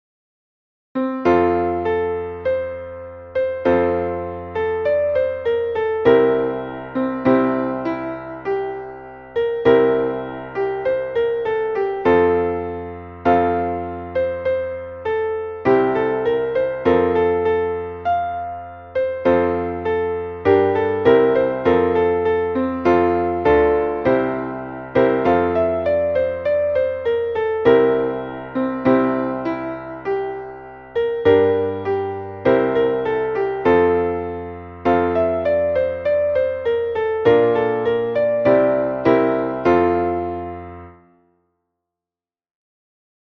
Traditionelles Weihnachtslied (19.